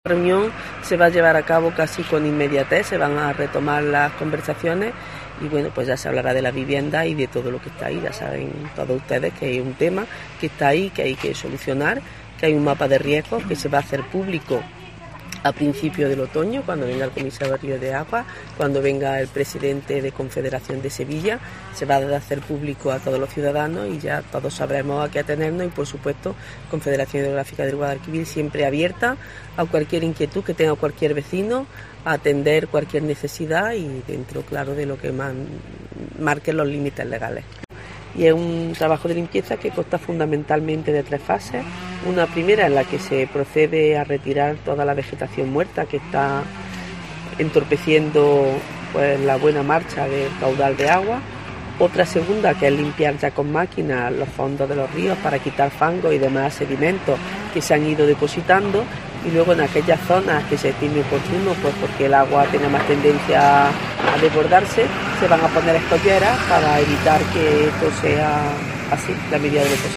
subdelegada sobre los Puentes de Jaén